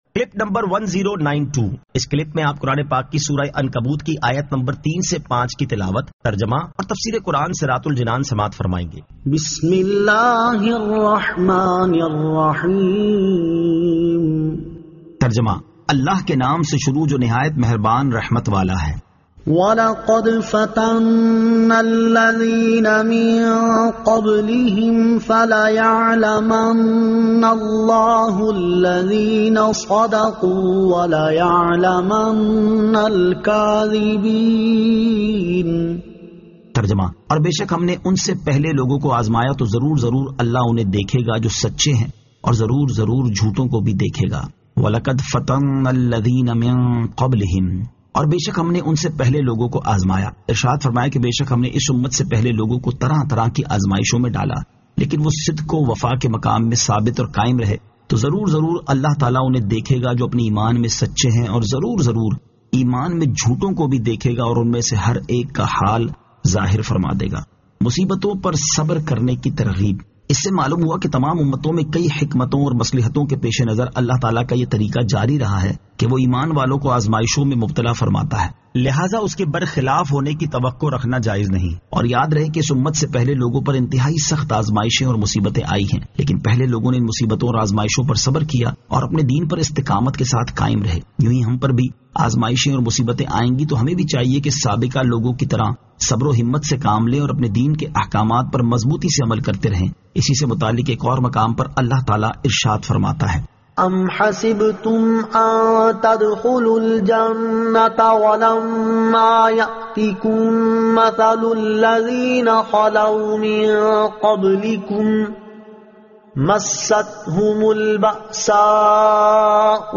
Surah Al-Ankabut 03 To 05 Tilawat , Tarjama , Tafseer